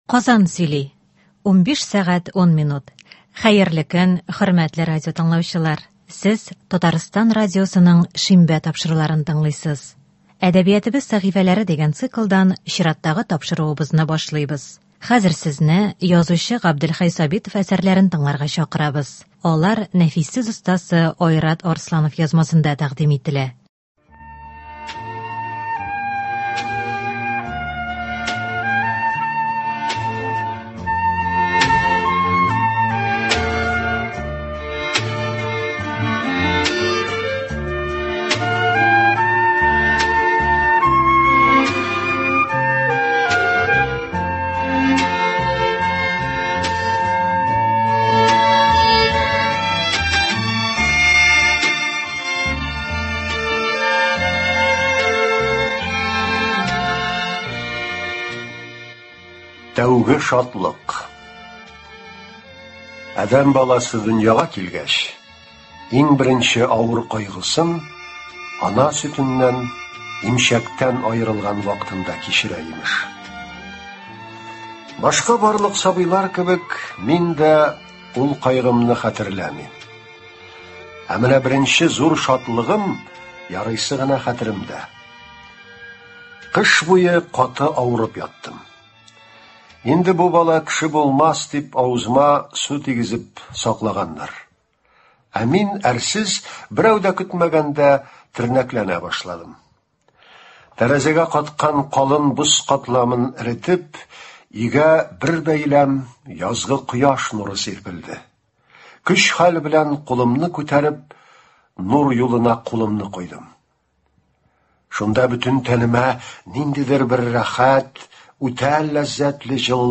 “Әдәбиятыбыз сәхифәләре” дигән циклдан сезне язучы Габделхәй Сабитов әсәрләрен тыңларга чакырабыз.